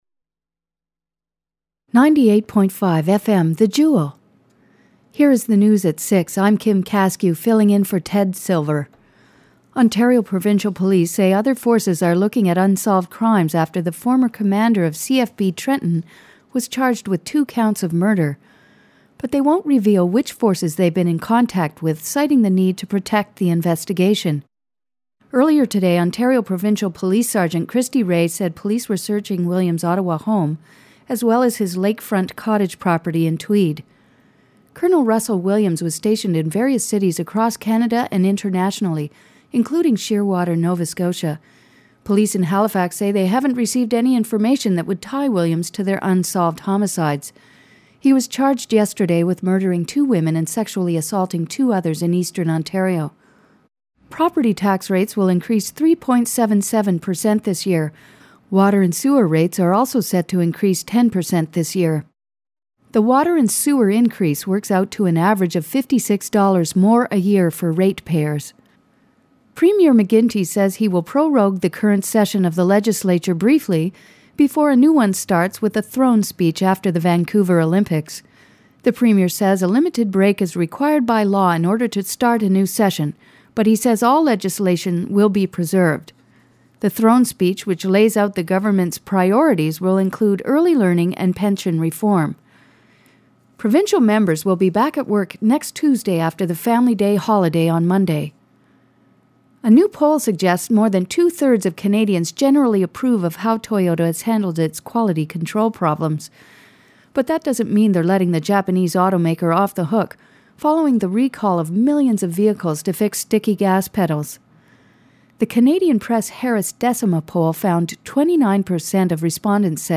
voiceoverNews.mp3